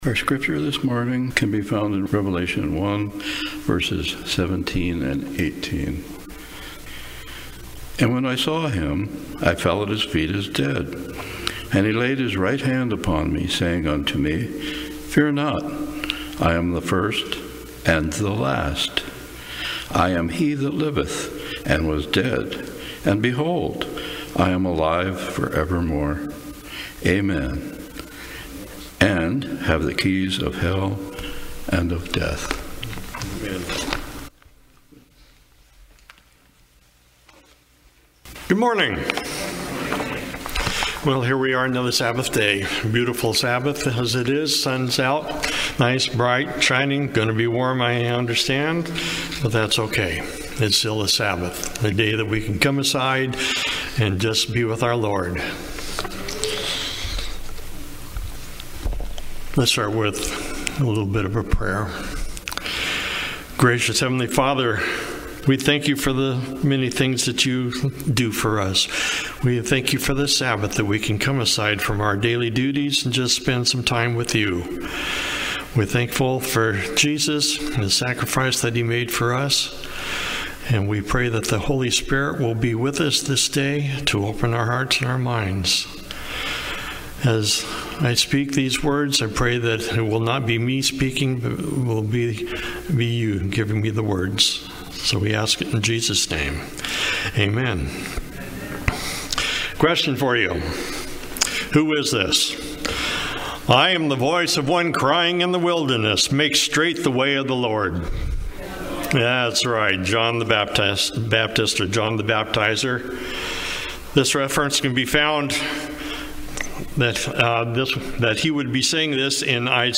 Seventh-day Adventist Church, Sutherlin Oregon
Sermons and Talks 2025